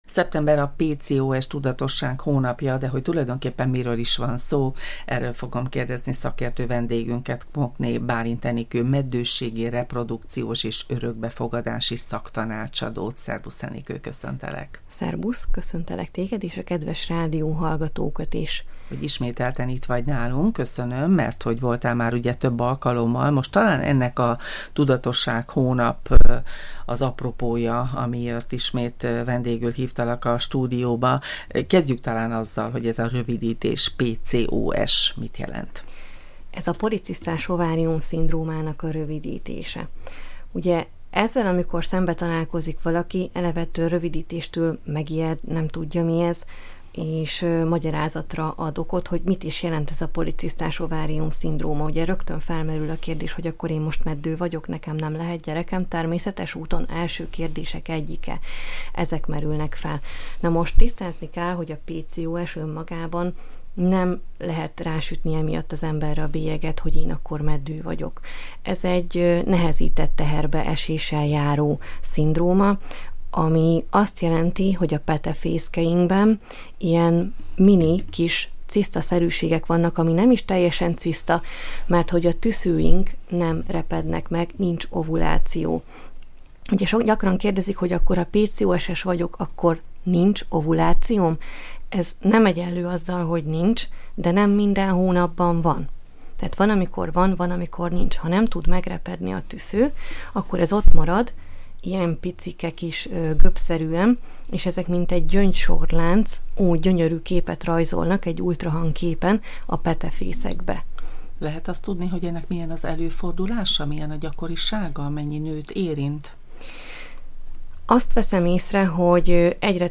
Szeptember a policisztás ovárium szindróma tudatosság hónapja. Erről is kérdeztük szakértő vendégünket